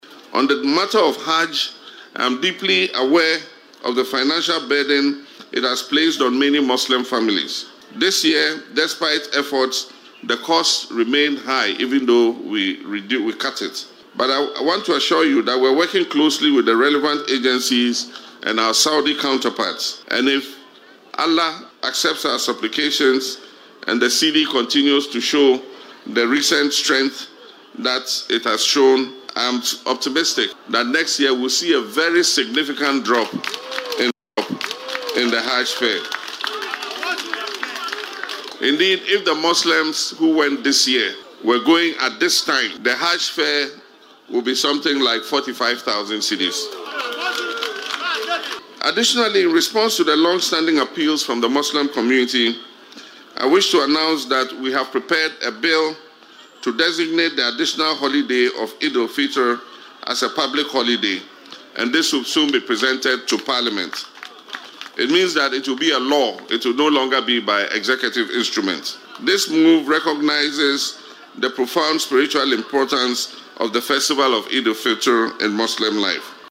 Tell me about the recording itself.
Addressing a gathering during the Eid-ul-Adha celebrations at the Black Star Square, the President acknowledged the financial burden many families face when planning the sacred pilgrimage to Mecca.